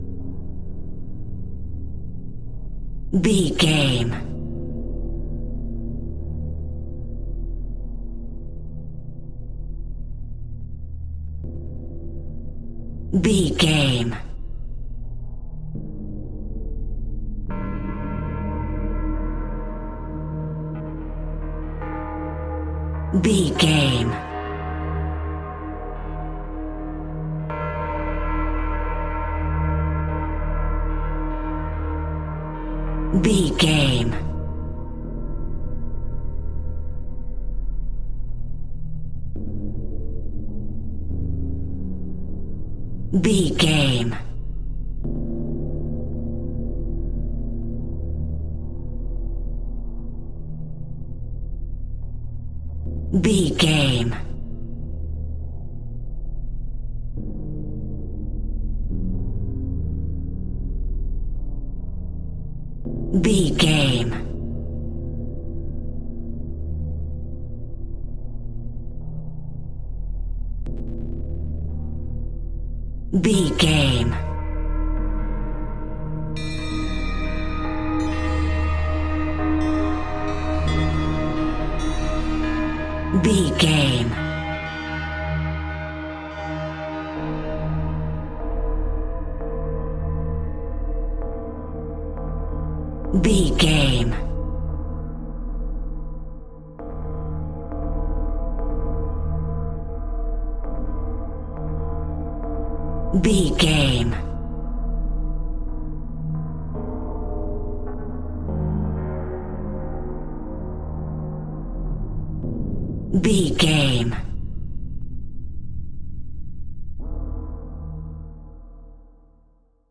In-crescendo
Atonal
ominous
haunting
eerie
strings
synthesiser
percussion
horror music